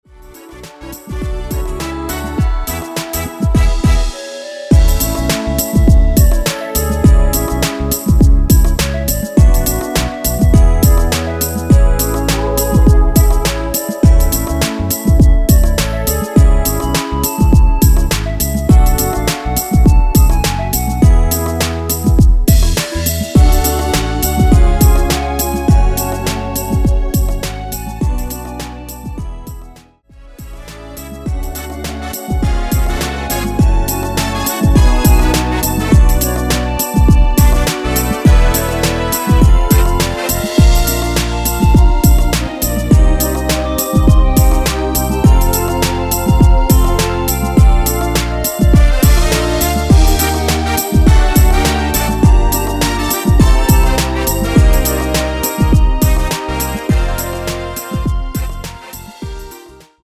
원키에서(-1)내린 멜로디 포함된 MR입니다.(미리듣기 확인)
멜로디 MR이라고 합니다.
앞부분30초, 뒷부분30초씩 편집해서 올려 드리고 있습니다.
중간에 음이 끈어지고 다시 나오는 이유는